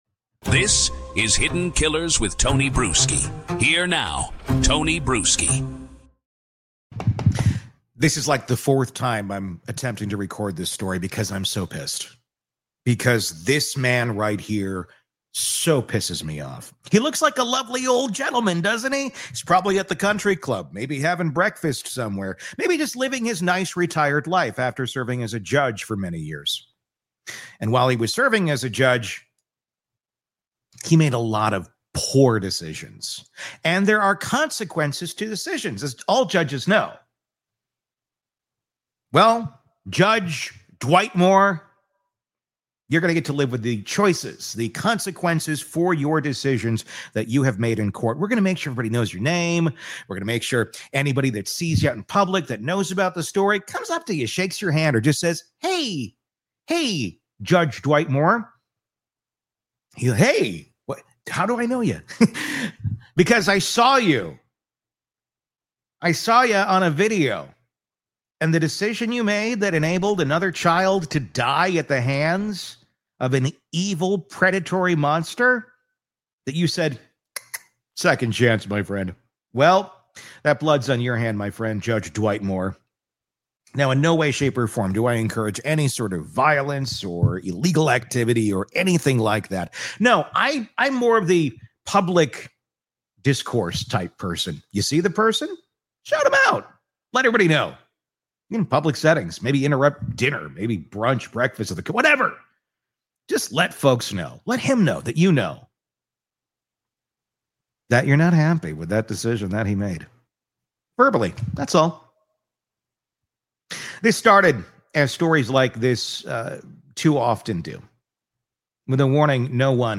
Full narrative told in our signature style—emotional, sharp, and grounded in facts.